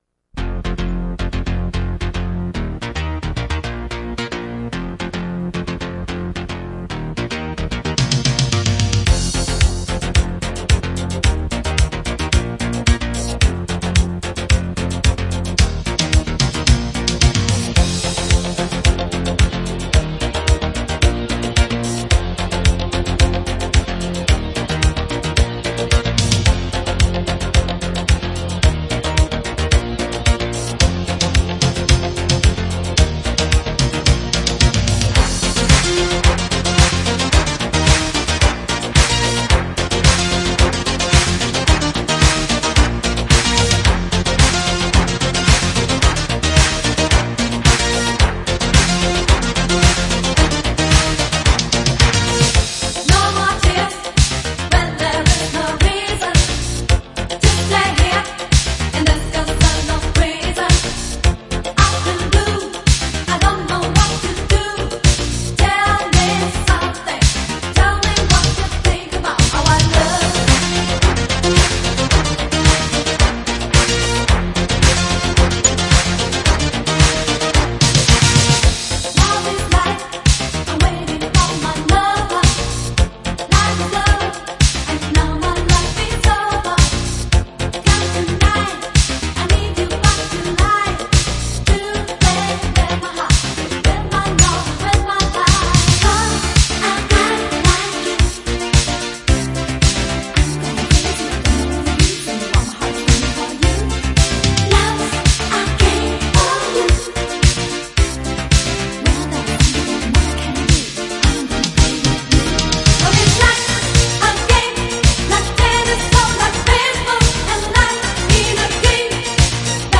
Extended Mix Remastered